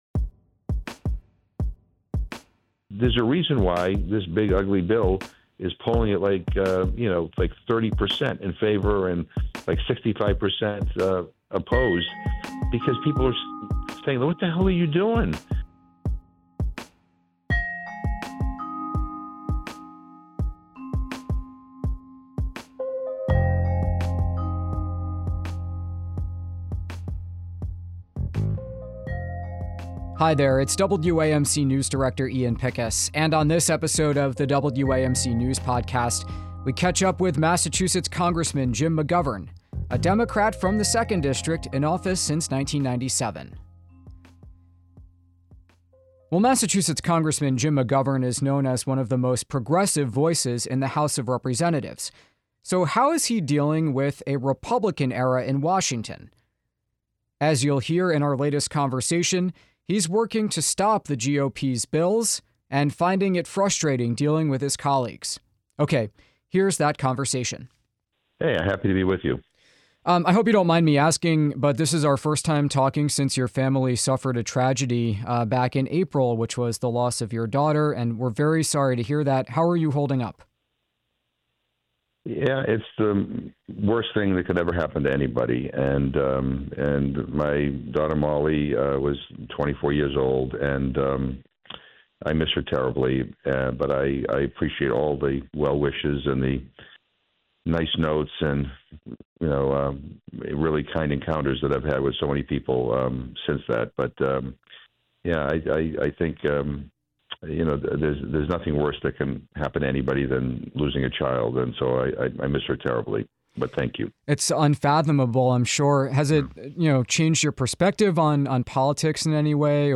We speak with Mass. Rep. Jim McGovern, a progressive Democrat from the 2nd District who is fiercely opposed to the "big, beautiful bill."